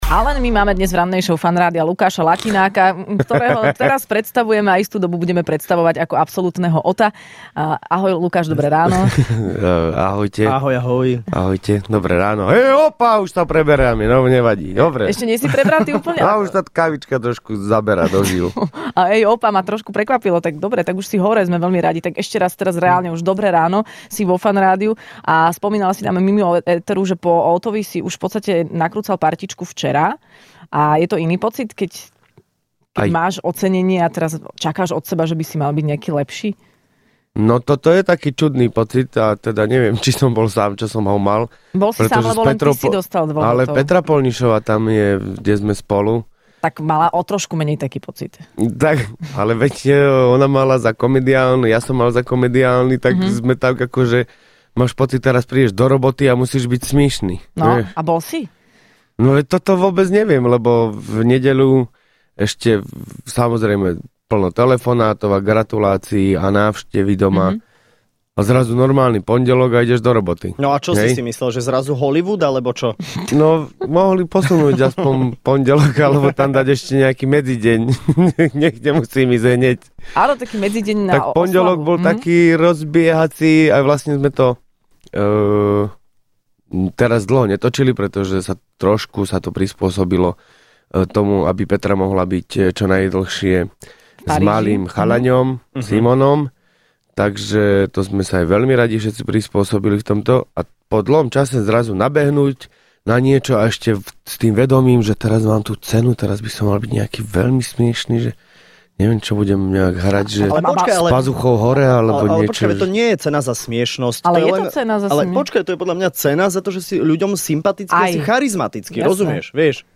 Hosťom v Rannej šou bol absolútny víťaz ocenenia OTO Lukáš Latinák.